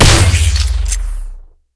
lasercannonfire.ogg